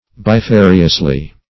bifariously - definition of bifariously - synonyms, pronunciation, spelling from Free Dictionary Search Result for " bifariously" : The Collaborative International Dictionary of English v.0.48: Bifariously \Bi*fa"ri*ous*ly\, adv.
bifariously.mp3